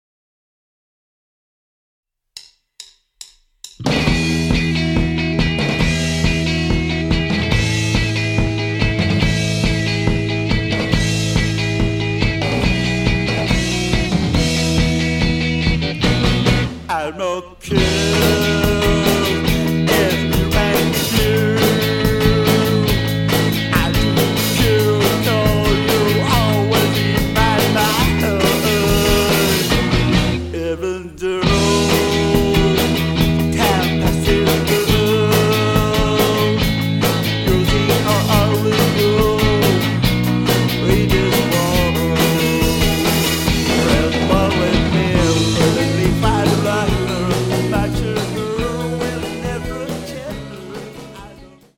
Power Pop